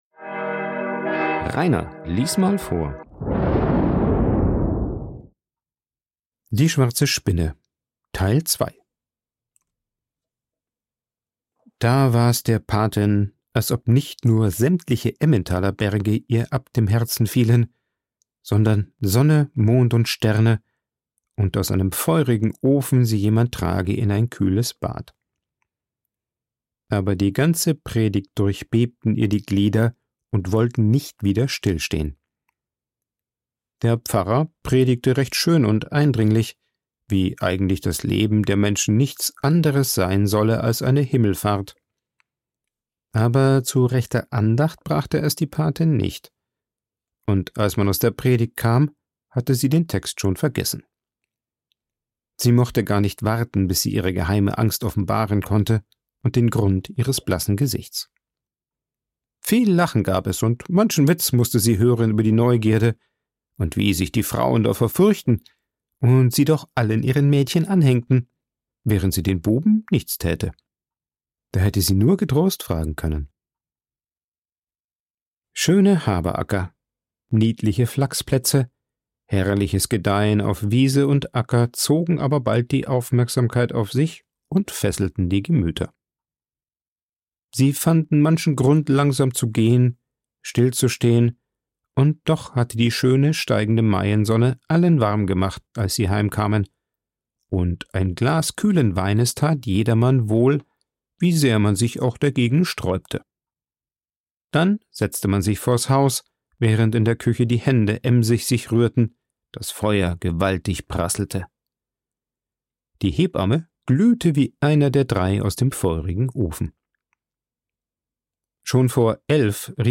aufgenommen und bearbeitet im Coworking Space Rayaworx, Santanyí, Mallorca.